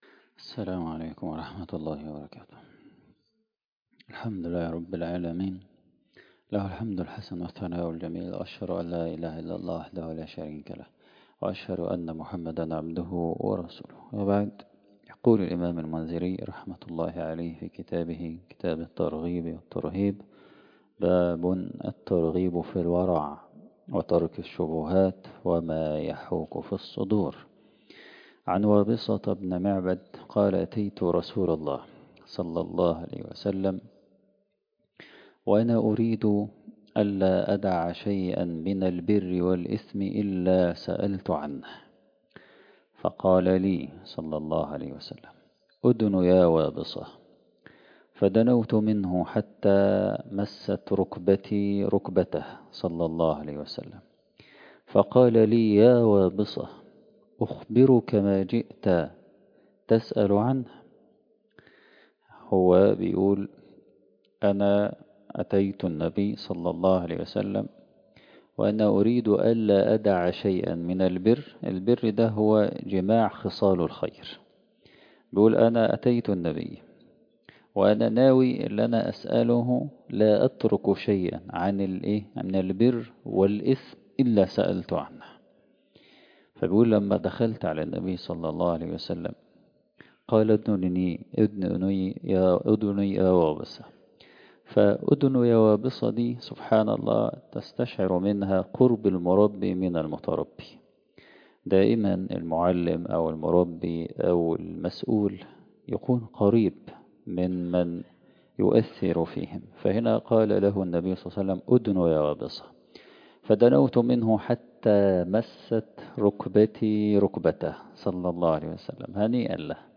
مسجد أحمد أبو أحمد